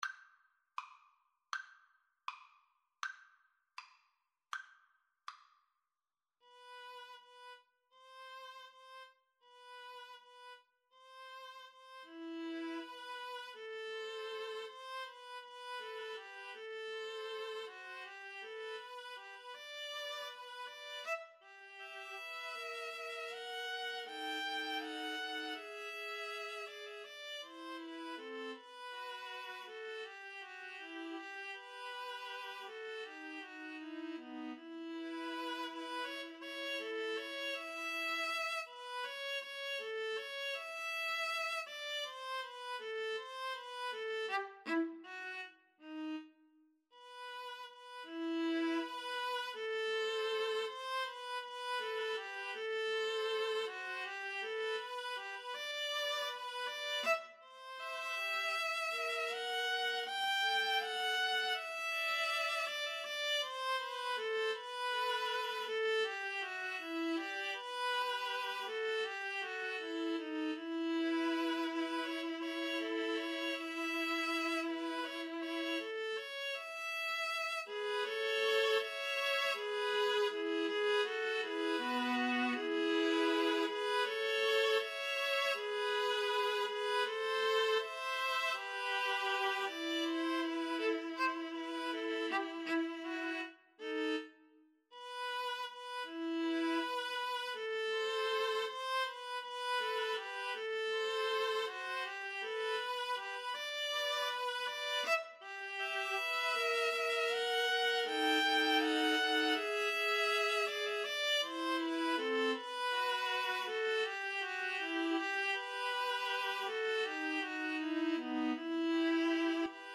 E minor (Sounding Pitch) (View more E minor Music for Viola Trio )
~ = 100 Andante
Classical (View more Classical Viola Trio Music)